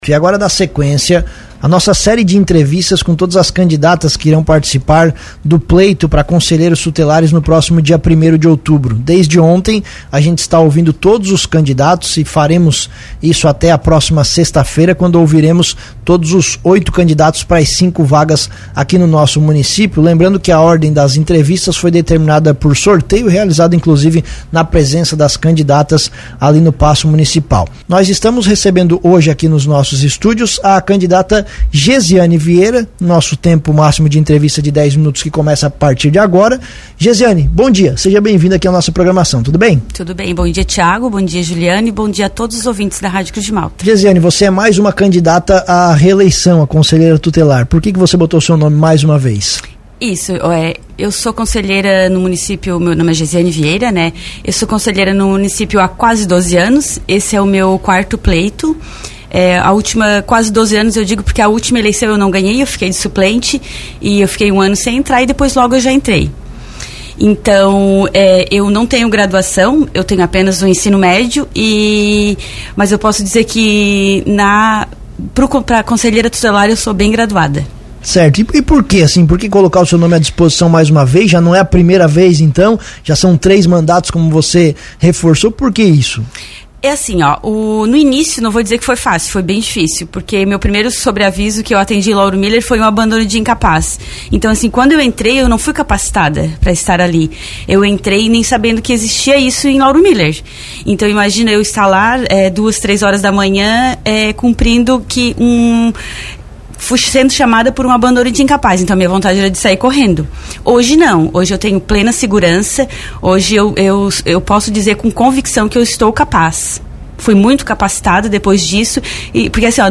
E para ajudar a população a escolher as novas conselheiras tutelares de Lauro Müller, o Cruz de Malta Notícias está com uma série de entrevistas com todas as candidatas que irão participar do pleito no dia 1º de outubro.